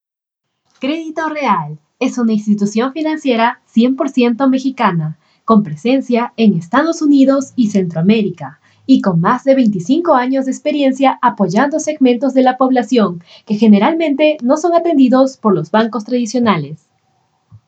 Tengo una voz profesional femenina con (Acento estándar de América del Sur). Ésta es grave pero puedo modularla a tonos agudos dependiendo de lo que se quiere transmitir.
Sprechprobe: Werbung (Muttersprache):
I have a professional female voice with (Standard Accent from South America).